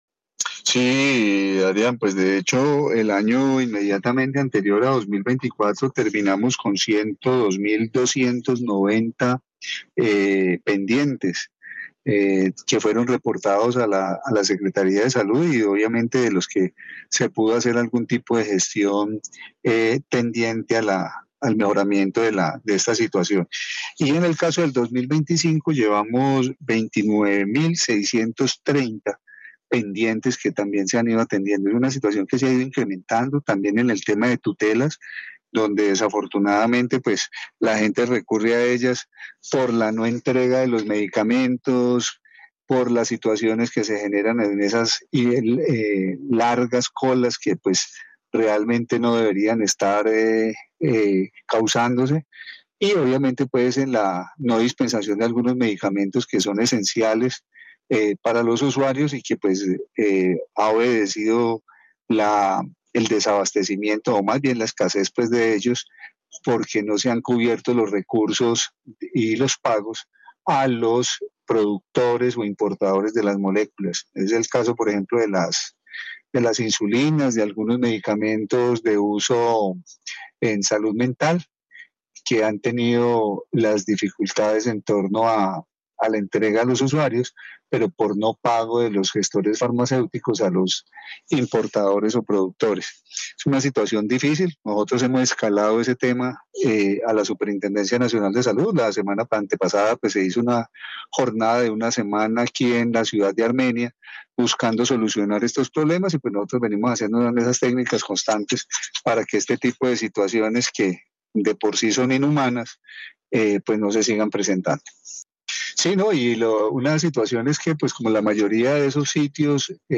Carlos Alberto Gómez, secretario salud, Quindío
En Caracol Radio Armenia, hablamos con el secretario de salud del Quindío, Carlos Alberto Gómez Chacón que manifestó si el incremento de medicamentos pendientes por entregar a los usuarios de las diferentes EPS en el departamento.